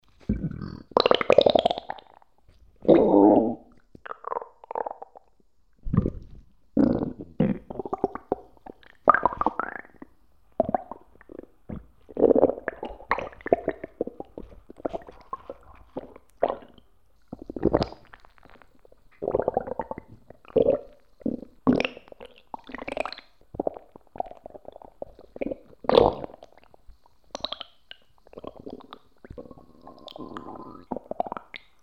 Звуки урчания в животе
На этой странице собраны натуральные звуки урчания в животе, которые можно скачать или слушать онлайн.
Разные вариации звуков: от легкого бурчания до интенсивного урчания после еды.
Булькающие звуки в животе человека